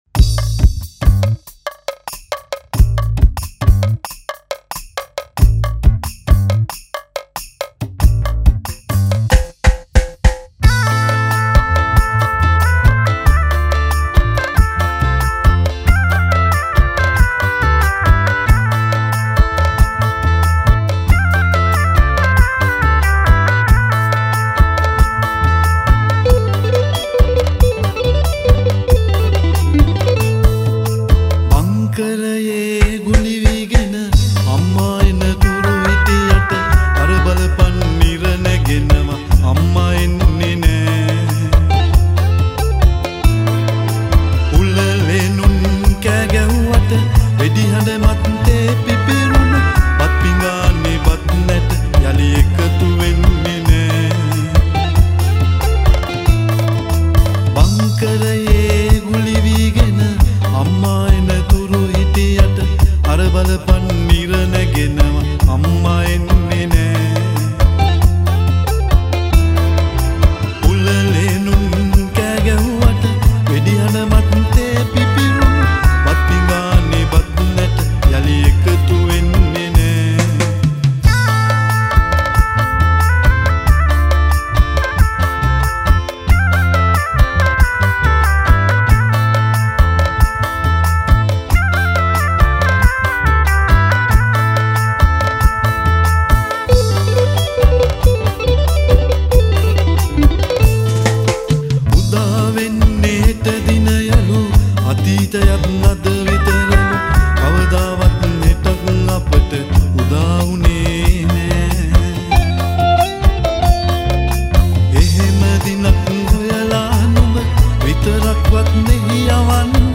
at Sumeega Studio Panadura Sri Lanka